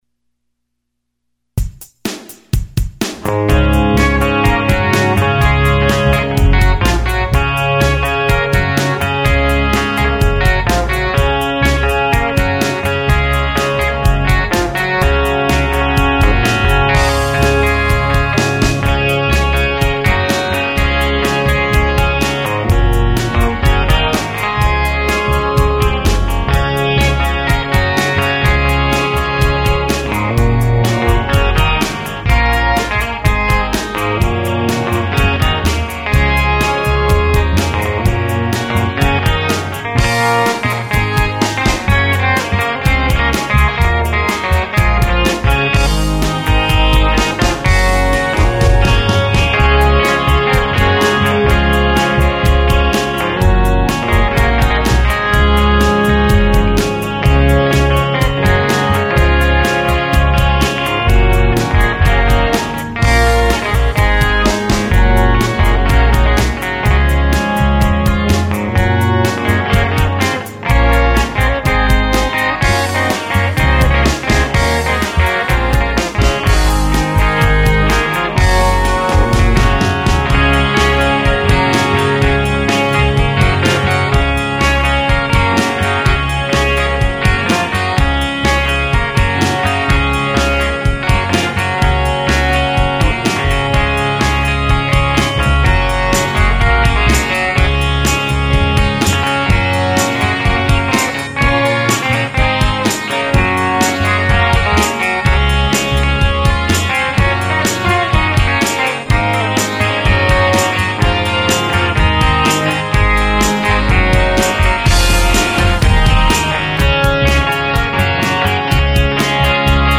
This is the second song I recorded, and of the 24 I've done so far, it is still my favorite; it's just simple and fun.
It's mainly fun with my Roger McGuinn 370-12 Ric, and some very basic drum and bass tracks -- something the Byrds might have done as a warm-up, say, the day after they got together.